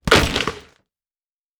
Foley Sports / Basketball / In The Net B.wav